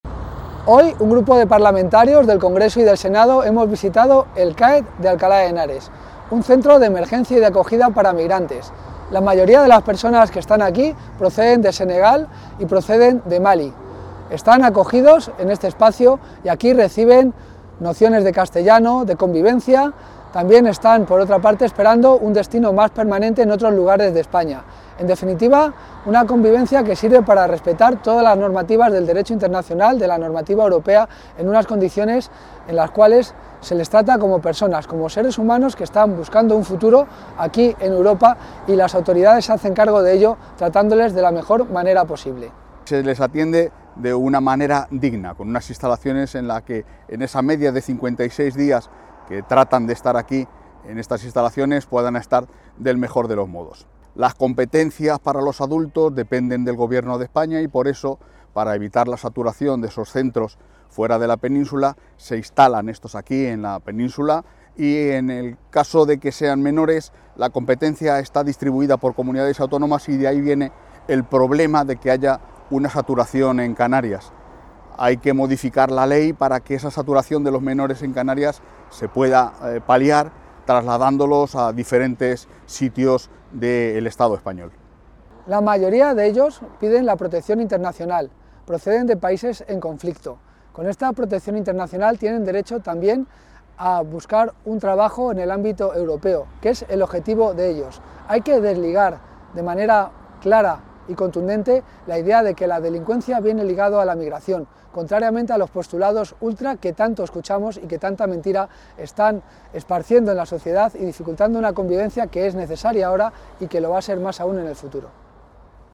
Declaraciones-CAED-Javier-Rodriguez-Palacios-y-Guillermo-Hita.mp3